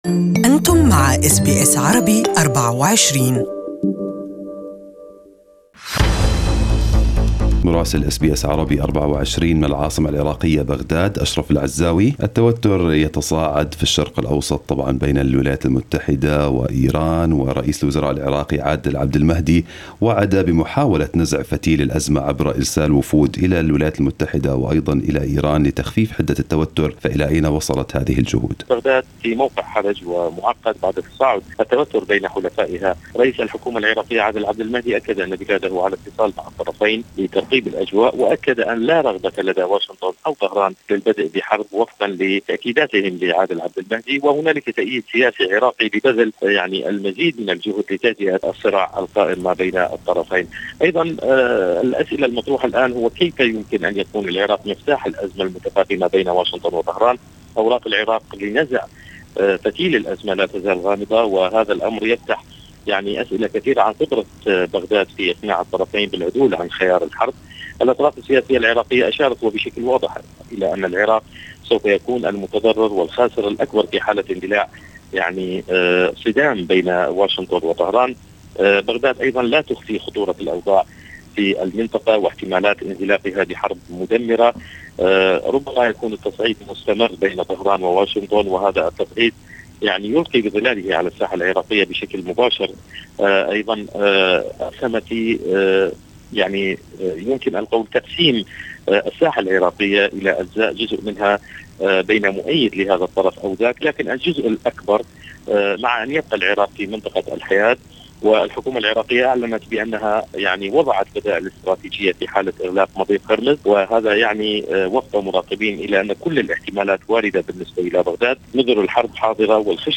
Weekly report from Baghdad, Iraq